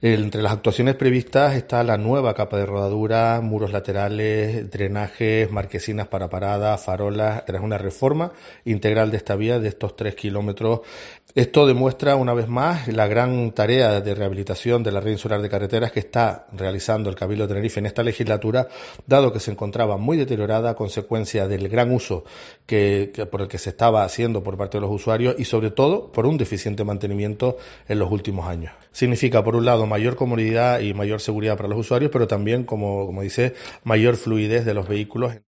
Enrique Arriaga, vicepresidente del Cabildo de Tenerife